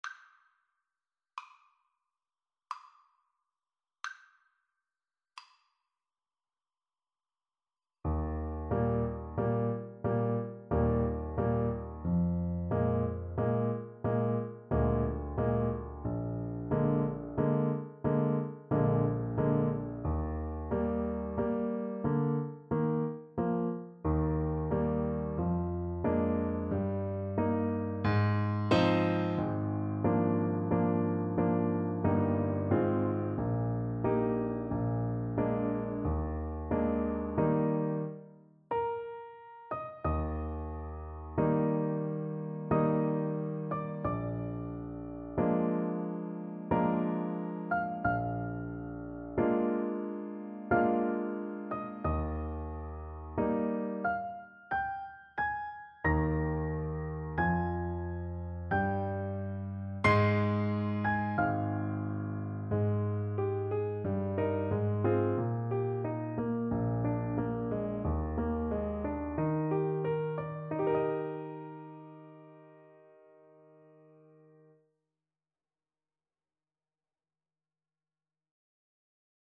3/4 (View more 3/4 Music)
Adagio =45
Classical (View more Classical Trumpet Music)